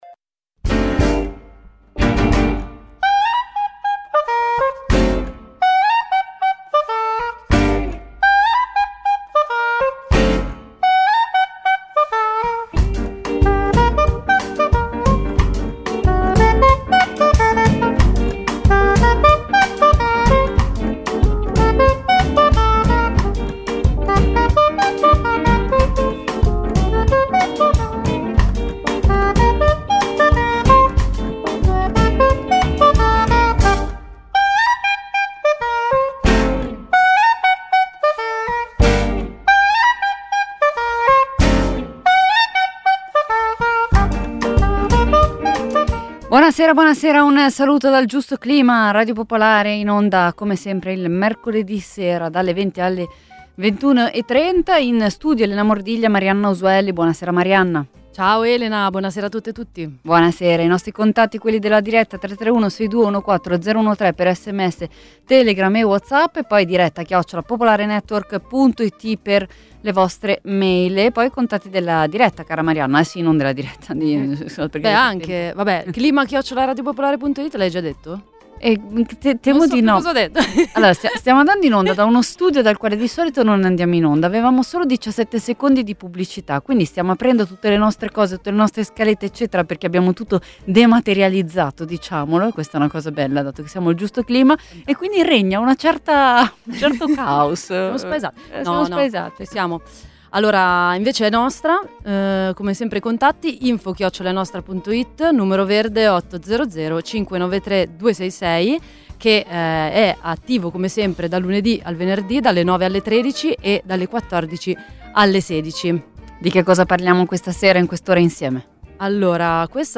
In onda tutti i mercoledì, dalle 20.30 alle 21.30. In studio